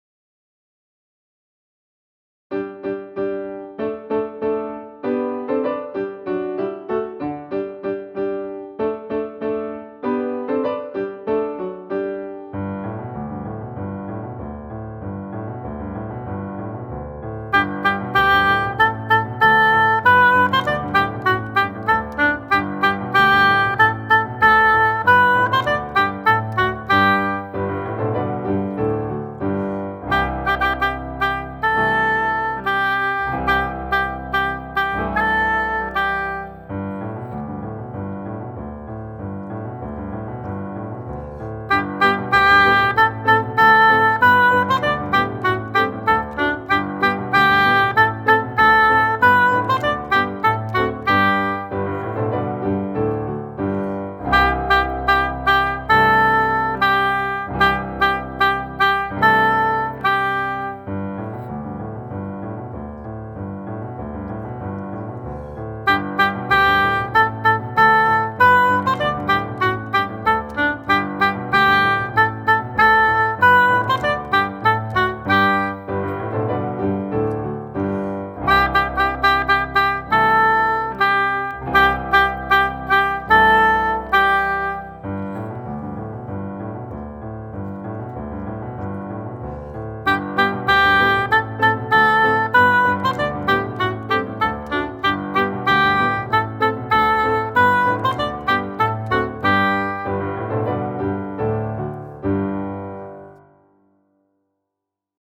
acvec hautbois